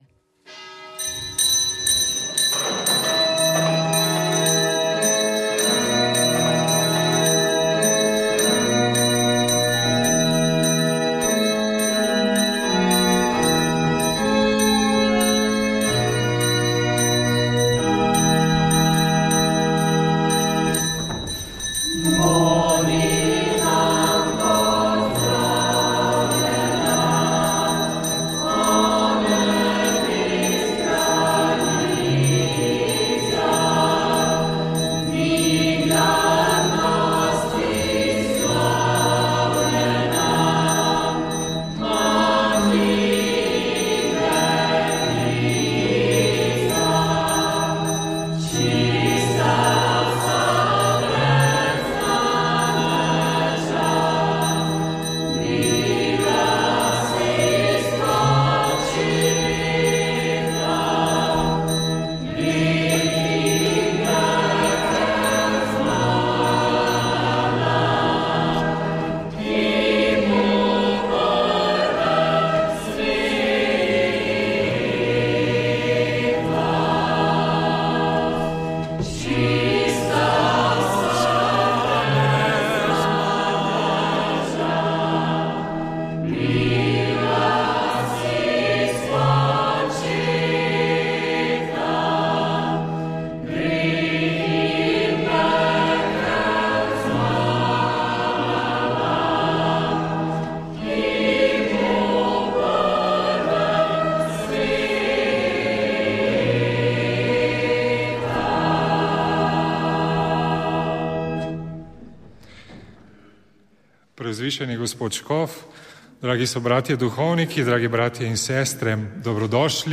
Prenos svete maše iz Marijinega romarskega svetišča Tri Fare Metlika.
Sveto mašo je, ob somaševanju številnih duhovnikov daroval upokojeni novomeški škof Andrej Glavan. Ob orgelski spremljavi je prepeval mladinski pevski zbor sv. Nikolaja župnije Metlika